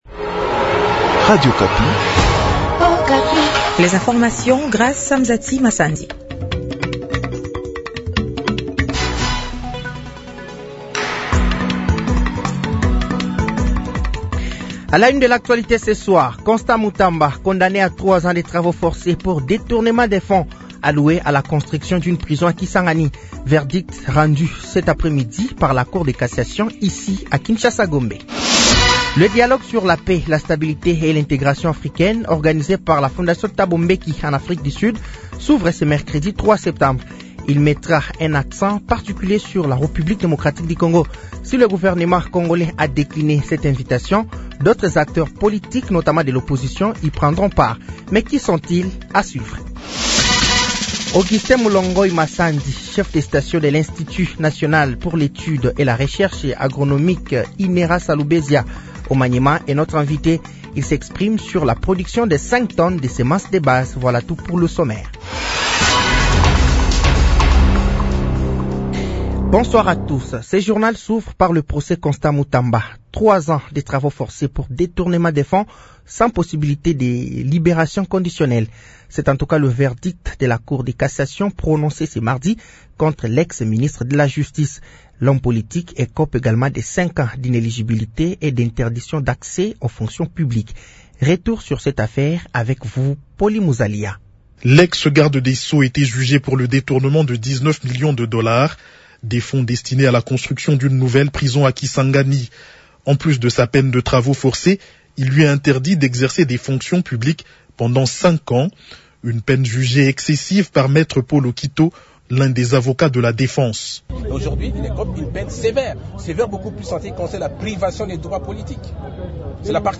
Journal français de 18h de ce mardi 02 septembre 2025